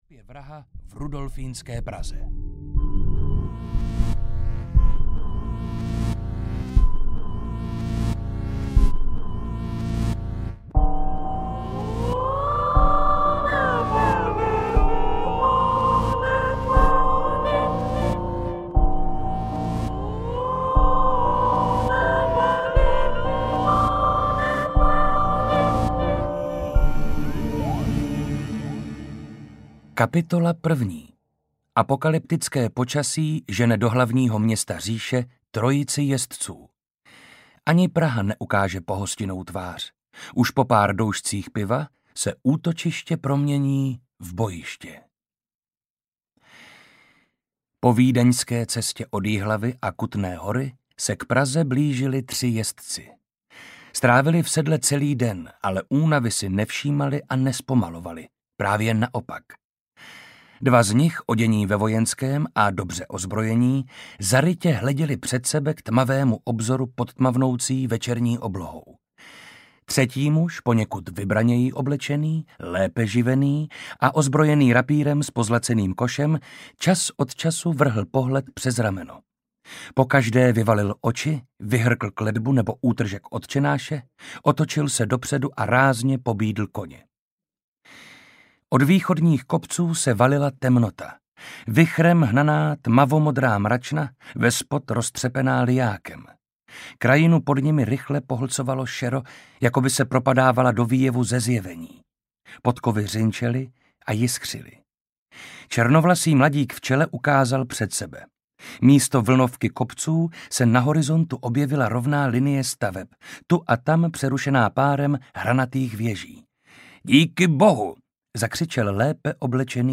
Krev prvorozených audiokniha
Ukázka z knihy
• InterpretMarek Holý